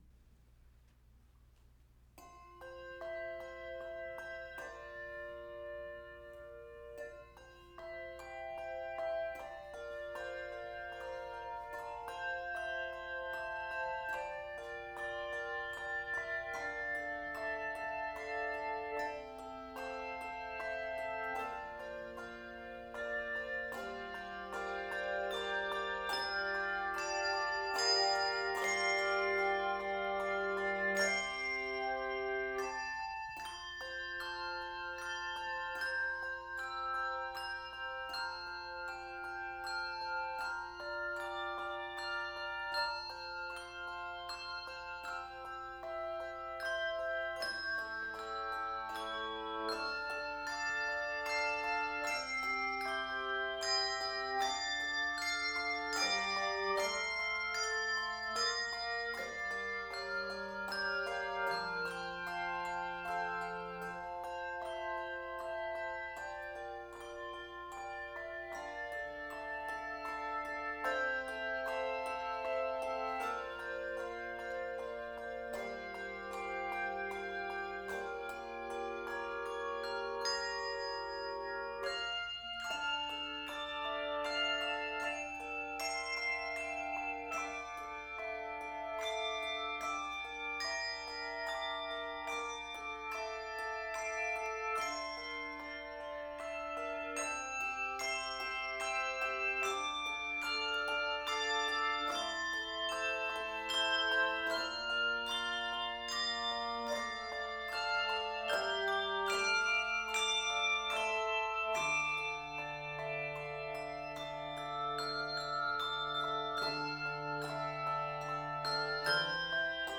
Voicing: Handbells 3 Octave